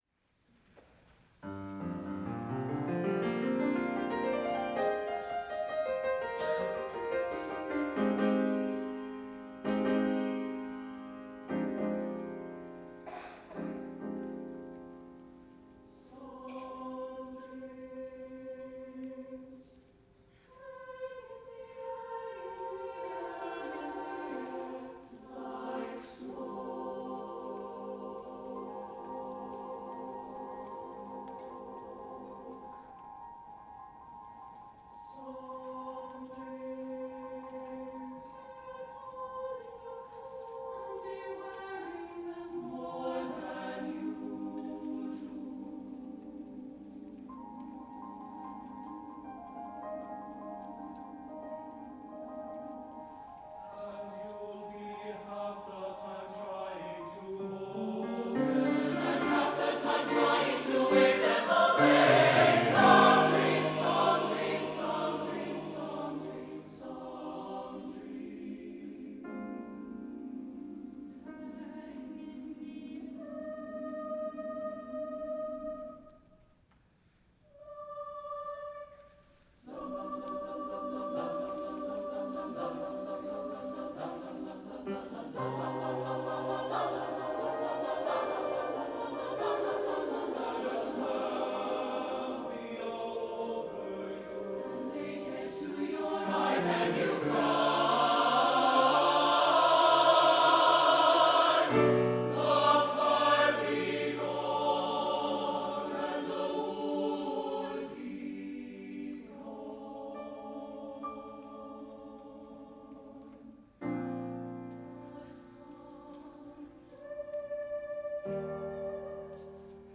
for SATB Chorus and Piano* (or Strings) (1992)